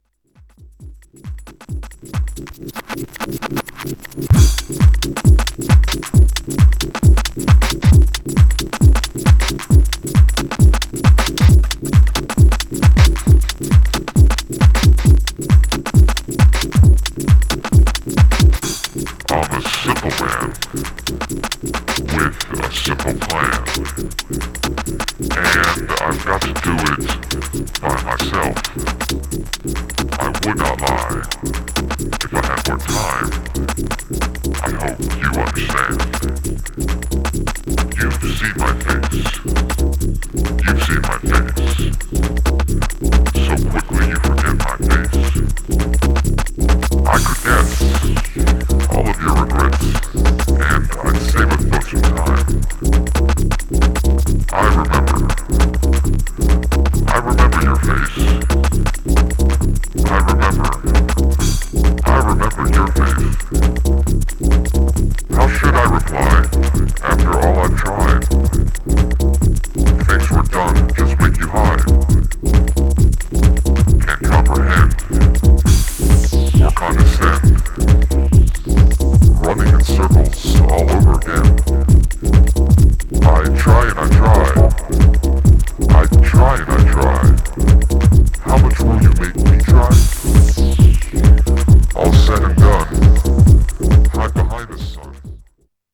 Styl: Techno, Breaks/Breakbeat